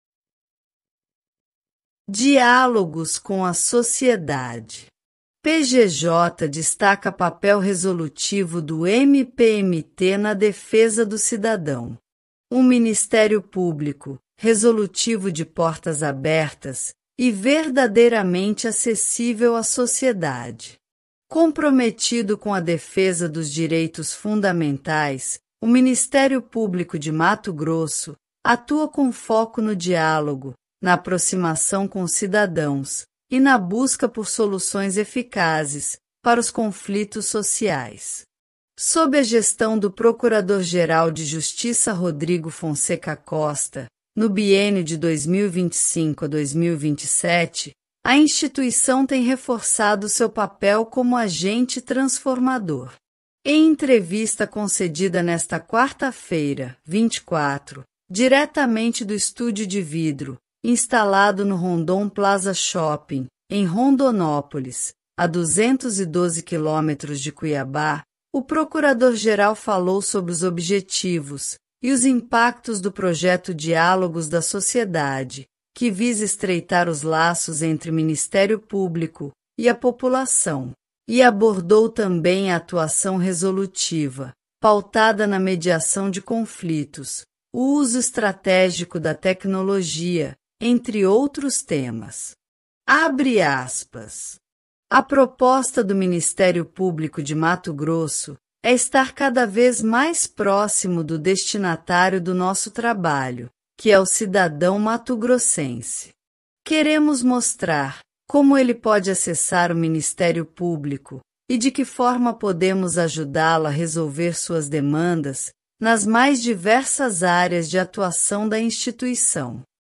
Em entrevista concedida nesta quarta-feira (24), diretamente do estúdio de vidro instalado no Rondon Plaza Shopping, em Rondonópolis (a 212 km de Cuiabá), o procurador-geral falou sobre os objetivos e os impactos do projeto Diálogos da Sociedade, que visa estreitar os laços entre o Ministério Público e a população, e abordou também a atuação resolutiva, pautada na mediação de conflitos, o uso estratégico da tecnologia, entre outros temas.